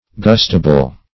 Gustable - definition of Gustable - synonyms, pronunciation, spelling from Free Dictionary
Gustable \Gust"a*ble\, a. [See Gust, v.]